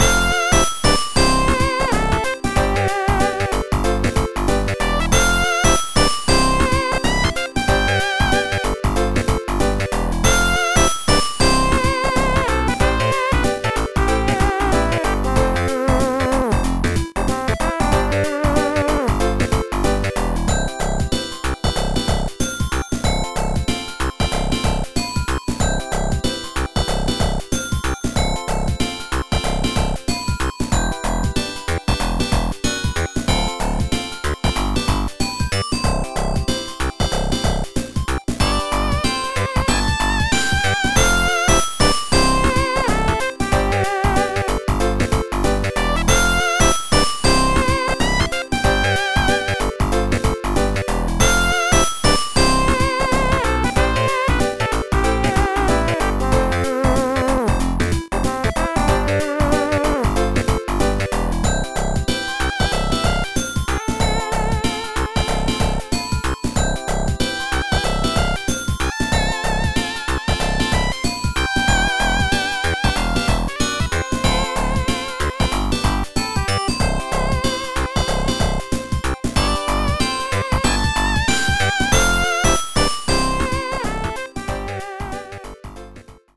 download FM yayyyy 8bc version